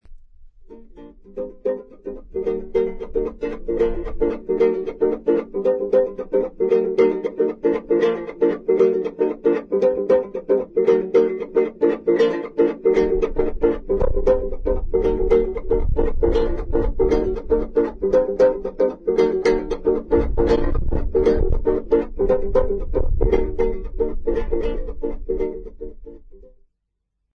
Folk songs, Xhosa South Africa
Stringed instrument music South Africa
Africa South Africa Lumko, Eastern Cape sa
field recordings
Xhosa dance tune accompanied by the three string guitar.